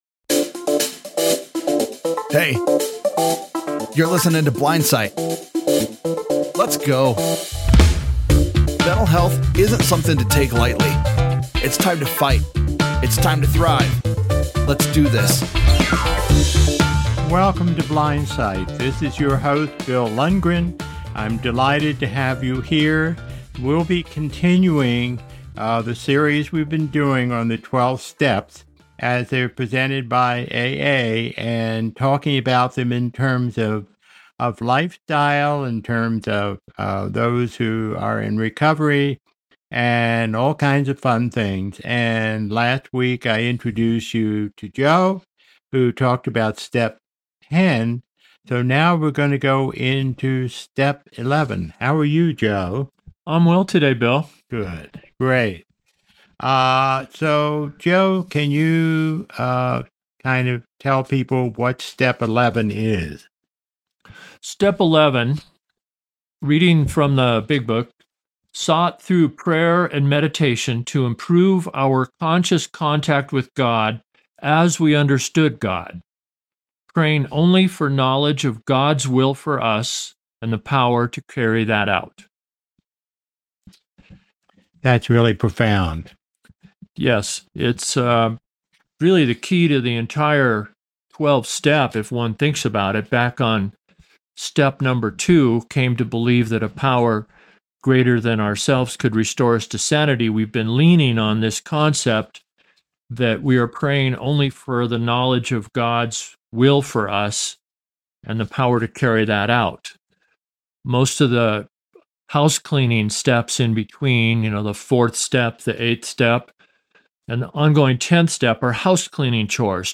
With practical advice and insightful anecdotes, the hosts explore strategies to connect with others and build meaningful relationships. They also discuss reframing the concept of independence as interdependence and the importance of embracing vulnerability.